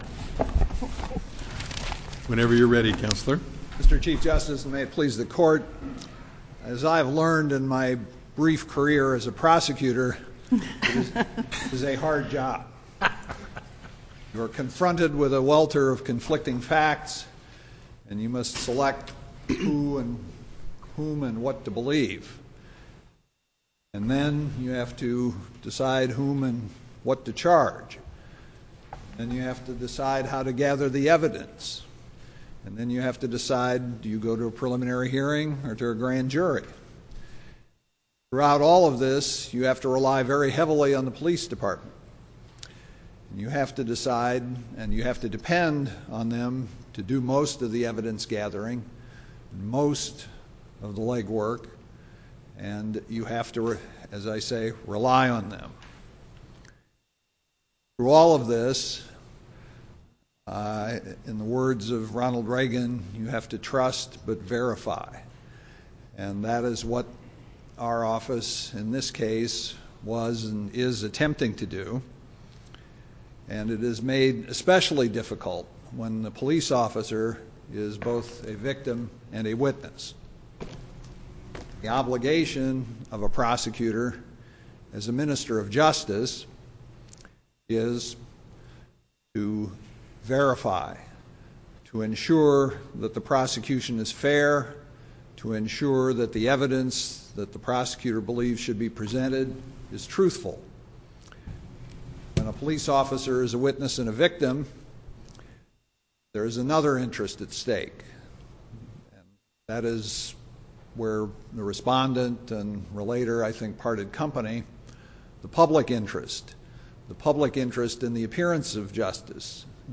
Constitutional challenge to search and seizure in drug possession case Listen to the oral argument